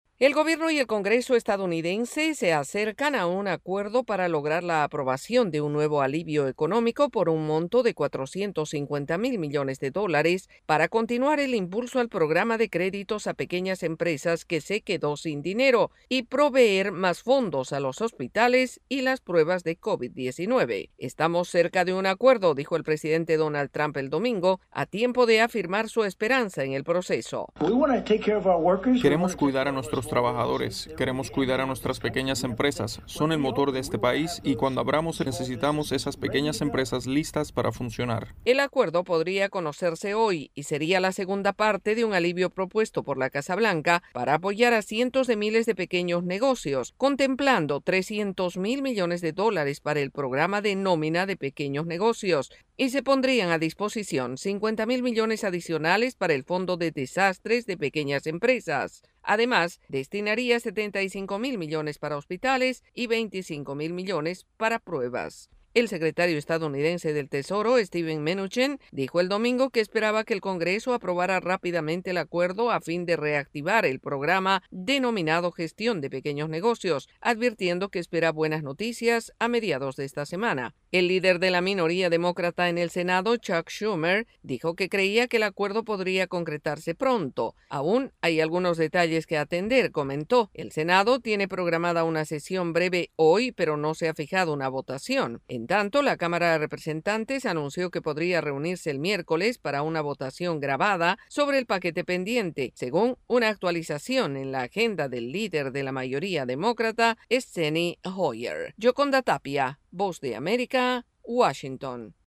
El gobierno del presidente Donald Trump y el Congreso esperan llegar a un acuerdo sobre un nuevo paquete de ayuda para pequeños negocios, hospitales y pruebas. El informe